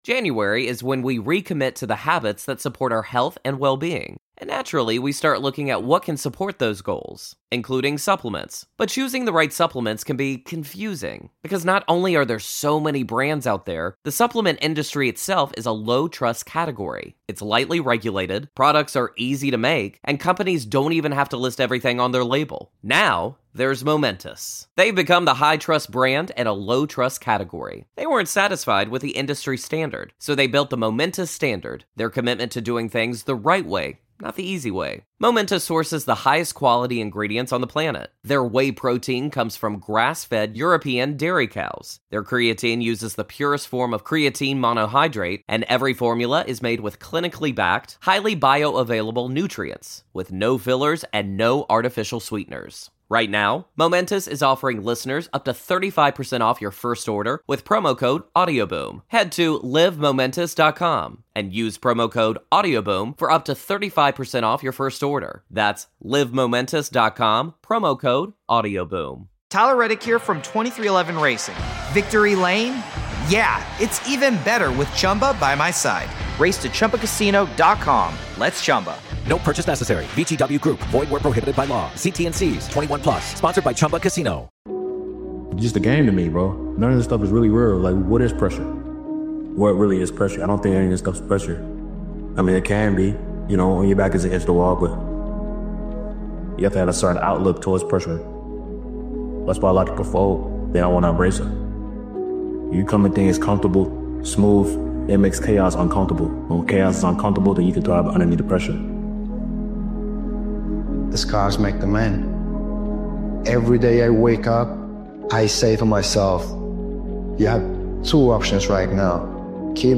This powerful motivational speech compilation speaks to the moment where repeating the same patterns is no longer an option.